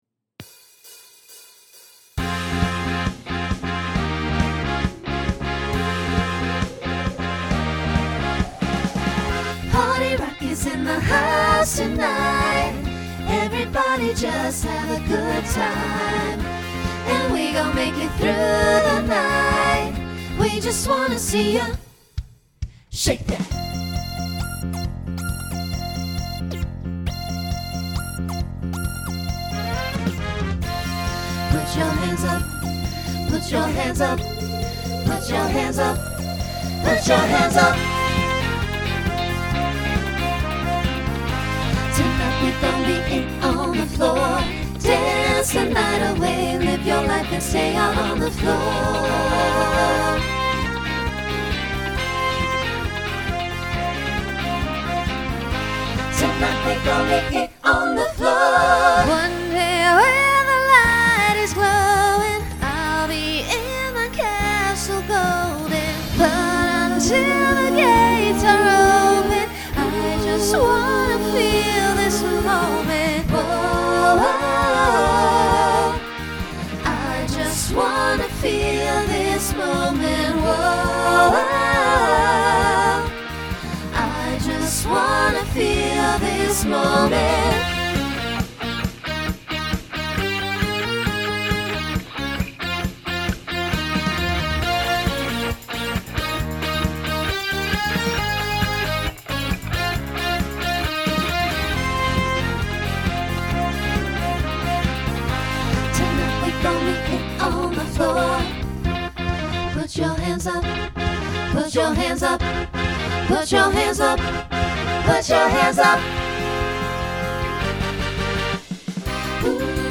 Genre Pop/Dance , Rock
Voicing SATB